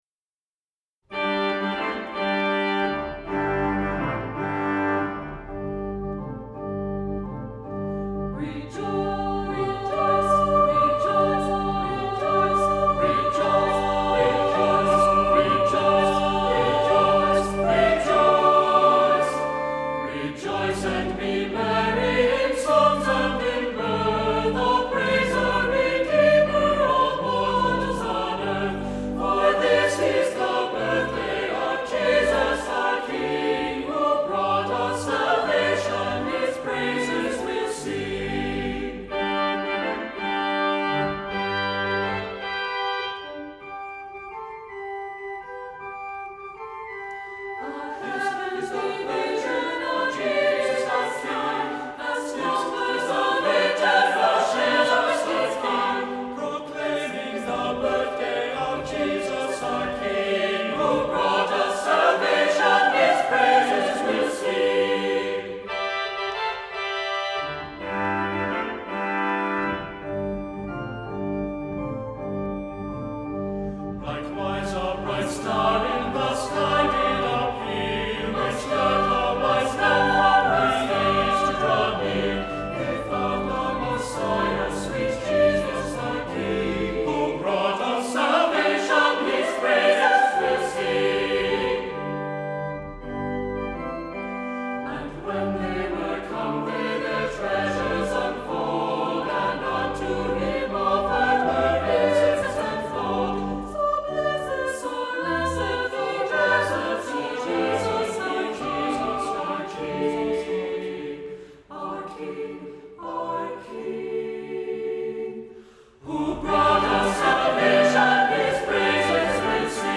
Accompaniment:      Keyboard
Music Category:      Choral